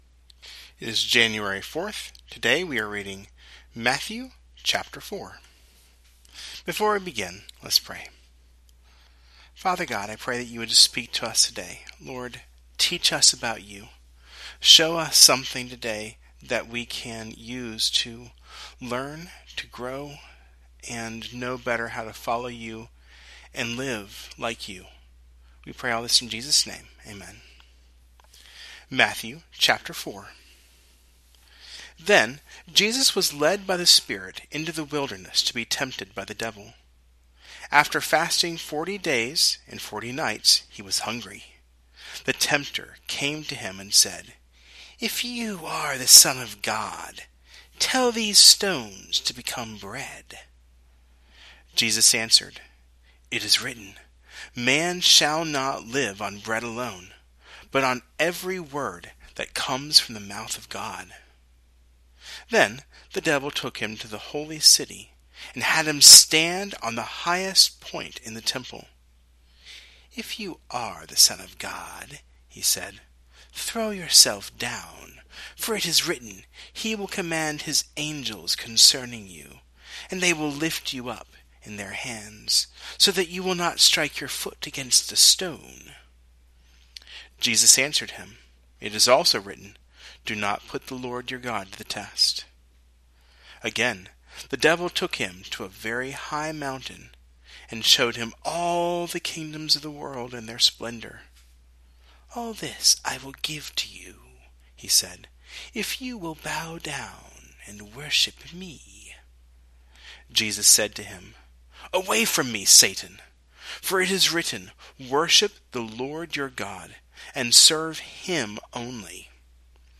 Today’s reading is from Matthew 4.